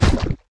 Index of /App/sound/monster/misterious_diseased_spear
drop_1.wav